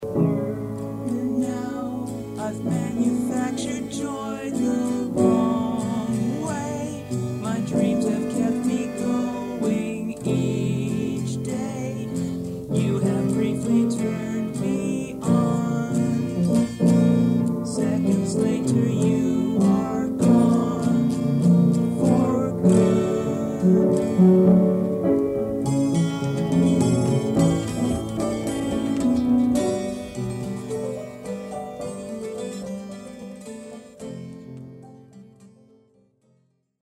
original demo